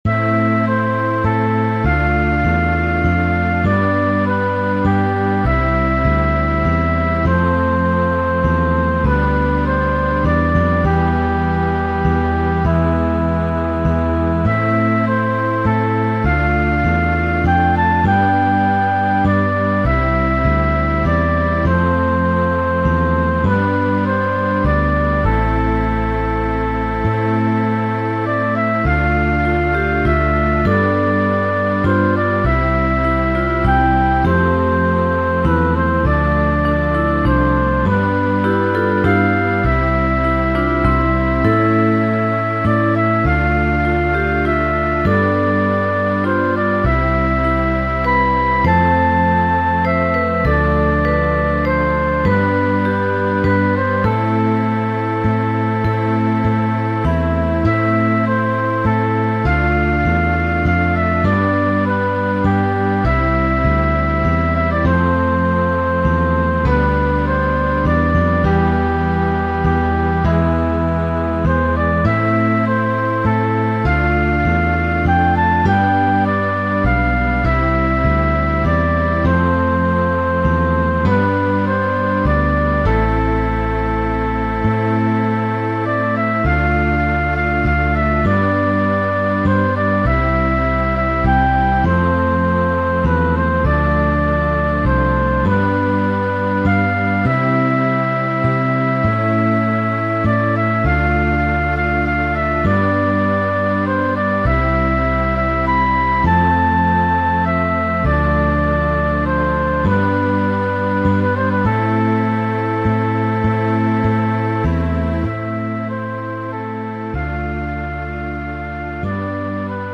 なわけで…悲しげな雰囲気の曲にしてみました。